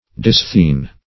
Search Result for " disthene" : The Collaborative International Dictionary of English v.0.48: Disthene \Dis"thene\, n. [Gr. di- = di`s- twice + ? force: cf. F. disth[`e]ne.]